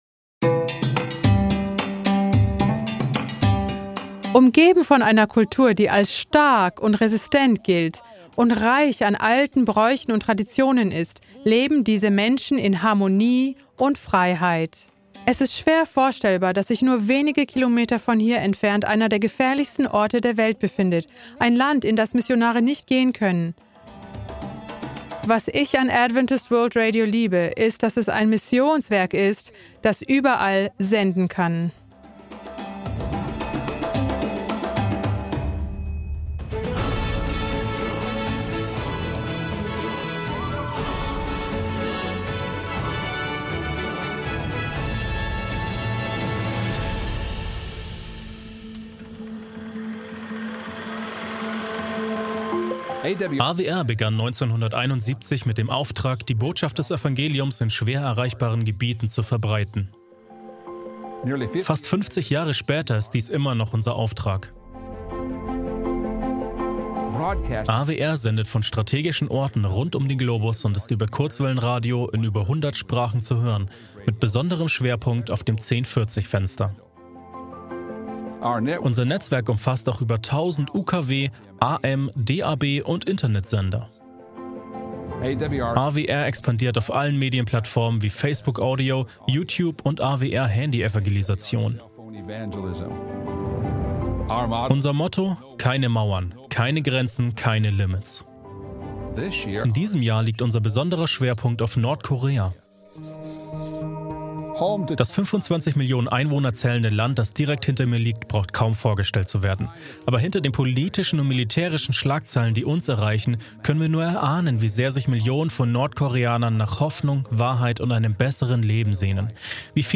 In diesem inspirierenden Vortrag wird die kraftvolle Mission von